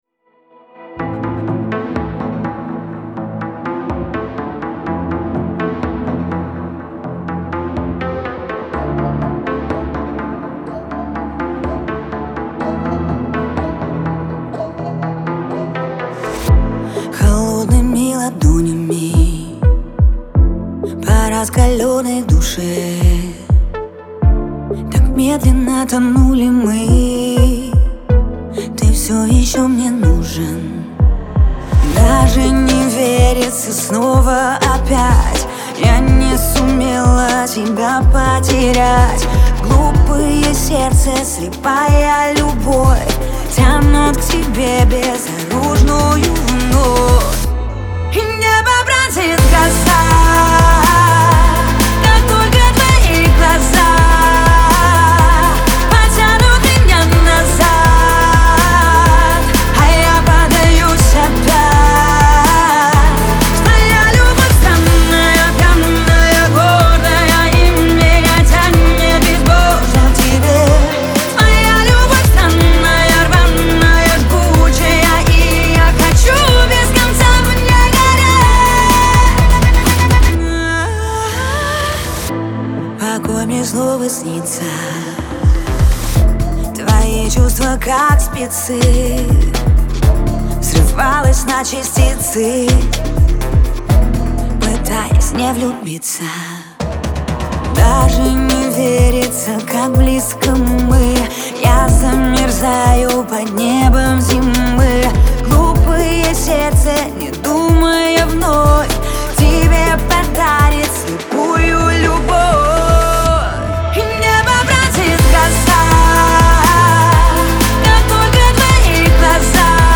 dance , эстрада , диско , pop